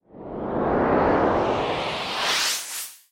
Звук плавного удаления из поля зрения
• Категория: Исчезновение, пропадание
• Качество: Высокое